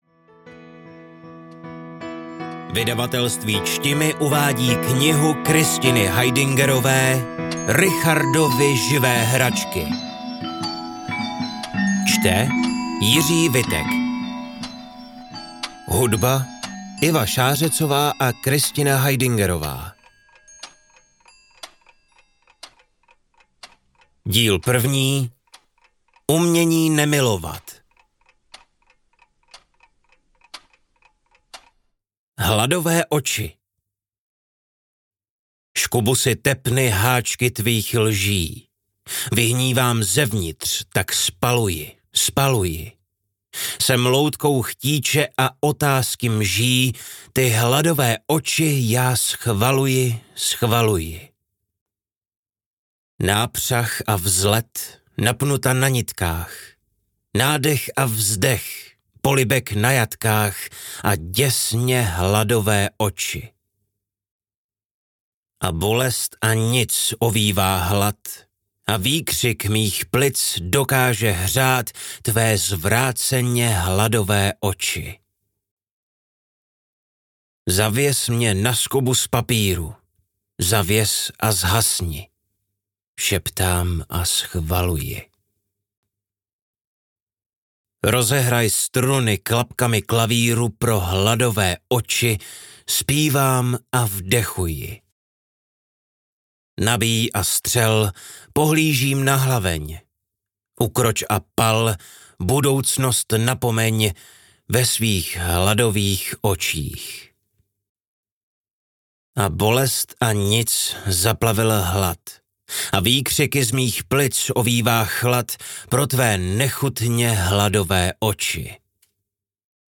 Richardovy živé hračky audiokniha
Ukázka z knihy